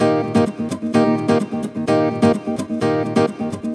VEH2 Nylon Guitar Kit 128BPM